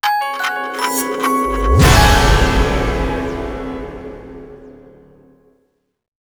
syssd_gameover.wav